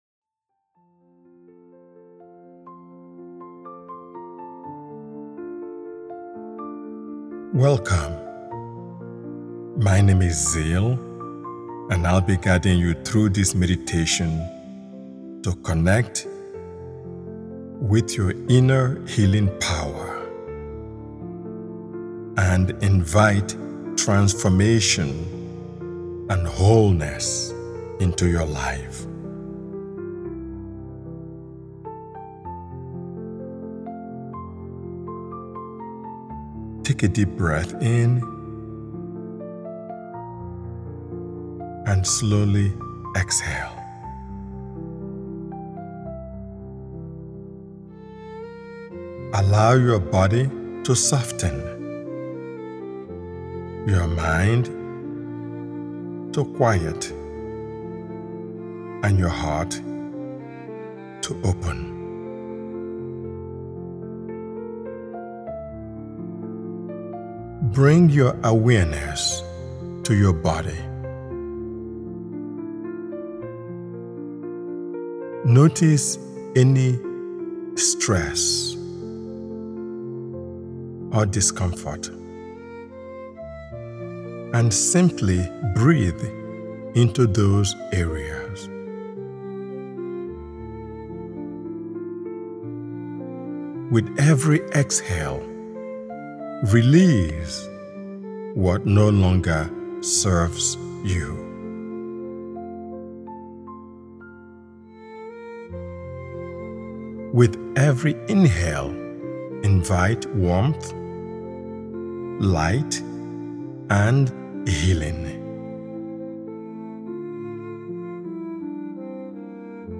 You Can Heal Your Life: Awaken Your Inner Healing Power invites you into a deeply restorative space where compassion, awareness, and renewal gently come together. This guided meditation is designed to help you reconnect with the healing capacity that already lives within you—a quiet, steady presence that can be nurtured through mindful attention and self-kindness.